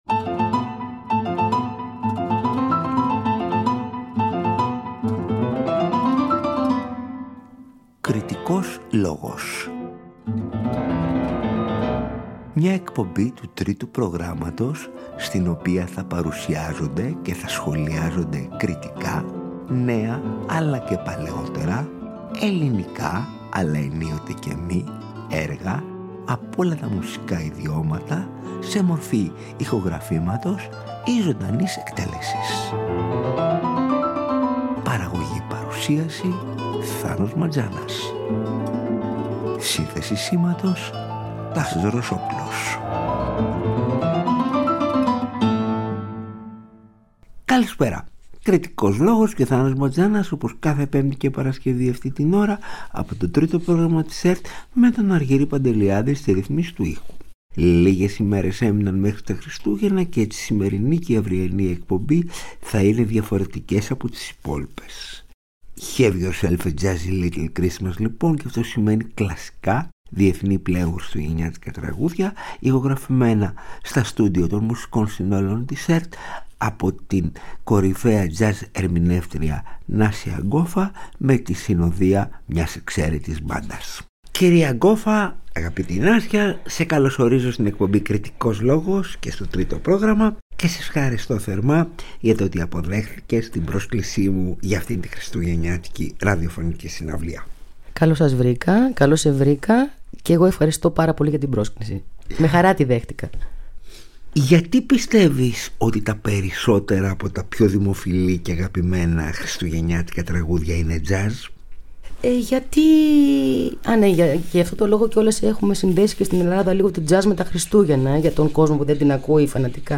jazz ερμηνεύτρια
πιάνο
κοντραμπάσο
ντραμς
σαξόφωνο